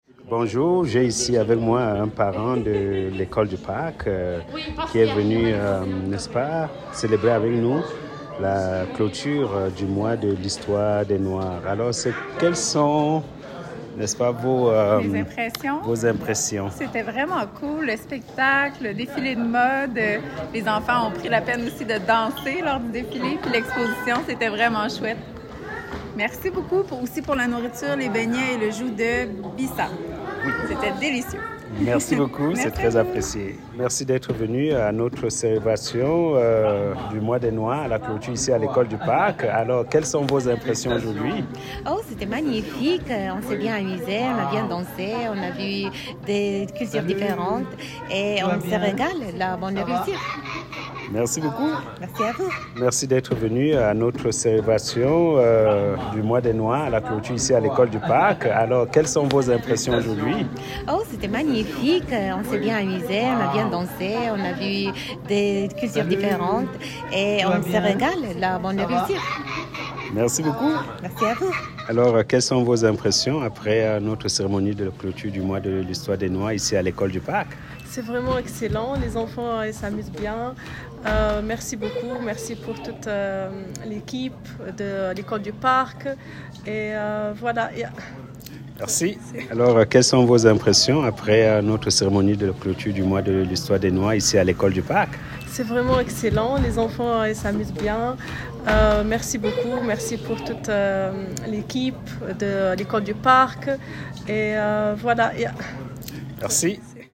Spectacle, danse, dégustation de mets africains et artisanat; une ambiance remplie de fierté et de joie lors de la soirée de clôture du Mois de l'histoire des Noirs à l'École du Parc de Regina.